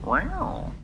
rawr.mp3